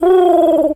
pigeon_call_calm_07.wav